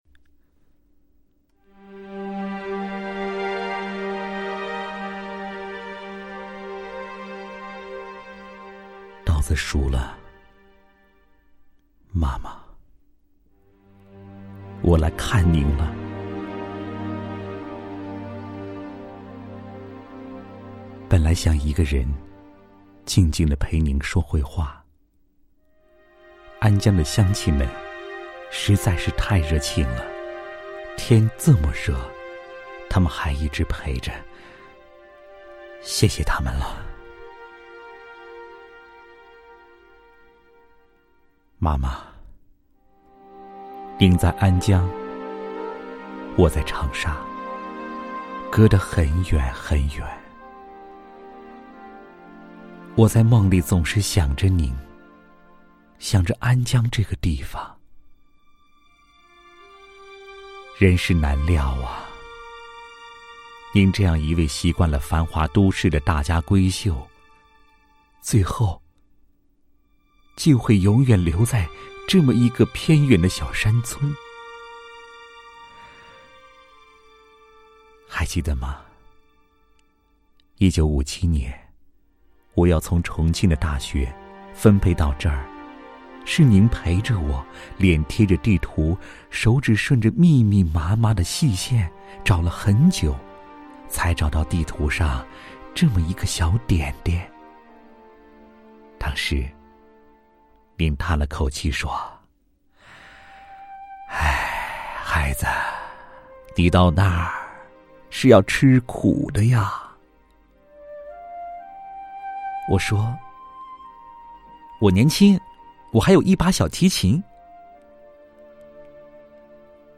朗诵